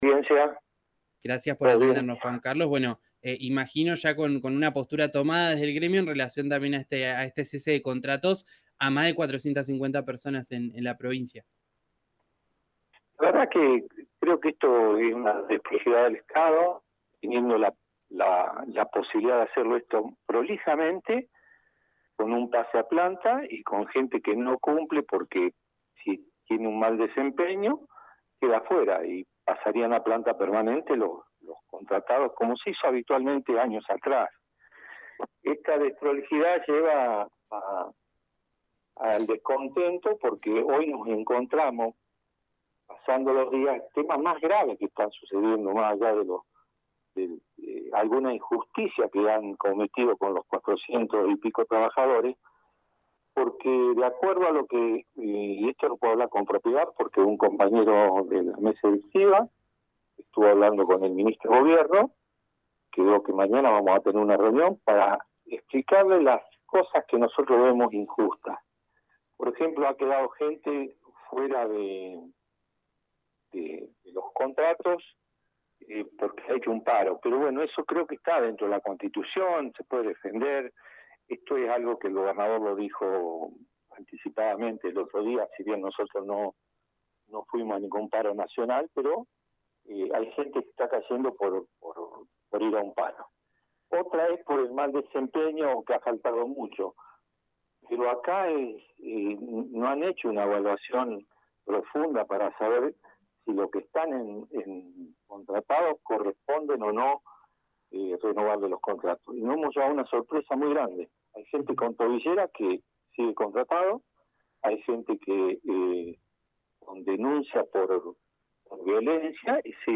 En diálogo con RÍO NEGRO RADIO el dirigente sindical dijo que el Gobierno podría hacer “prolijamente” un ordenamiento del personal con un pase a planta permanente en el que se detecte que “la gente que no cumple porque tiene mal desempeño quede afuera y pasarían todos los contratados que trabajan como se hizo habitualmente años atrás”.